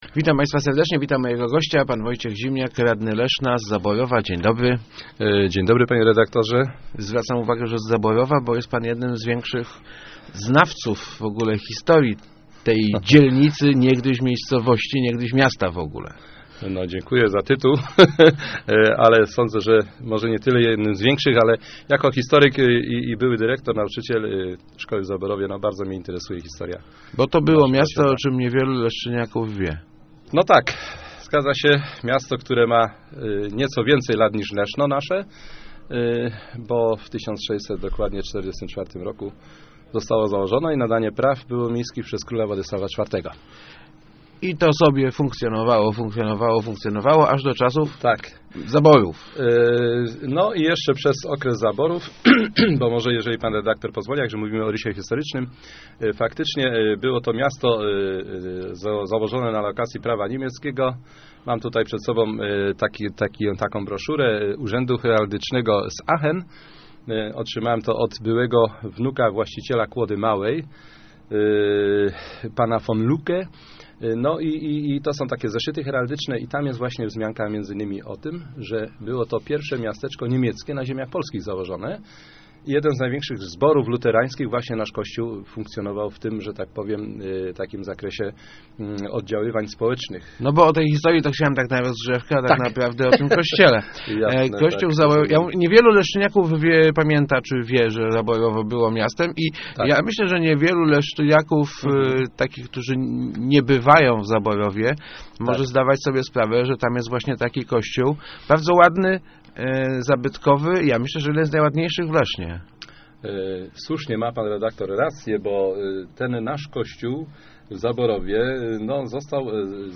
Kościół NMP Wniebowziętej na Zaborowie wymaga pilnego remontu - mówił w Rozmowach Elki radny Leszna Wojciech Zimniak. Najgorętszą potrzebą jest uratowanie wieży, chylącej się ku upadkowi.